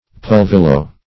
Search Result for " pulvillo" : The Collaborative International Dictionary of English v.0.48: Pulvillio \Pul*vil"li*o\, Pulvillo \Pul*vil"lo\, n. [See Pulvil .] A kind of perfume in the form of a powder, formerly much used, -- often in little bags.